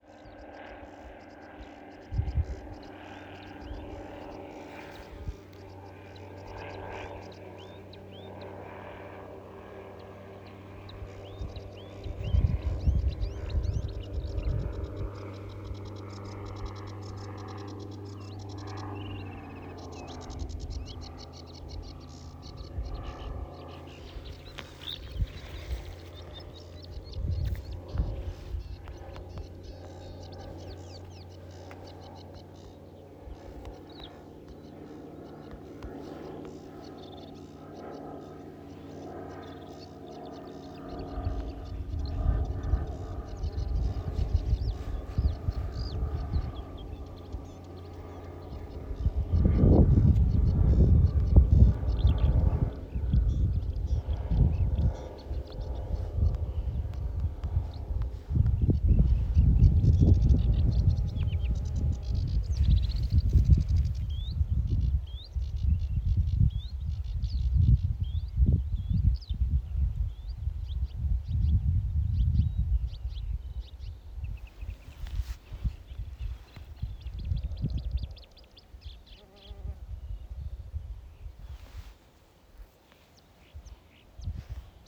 Sedge Warbler, Acrocephalus schoenobaenus
Administratīvā teritorijaCarnikavas novads
StatusSinging male in breeding season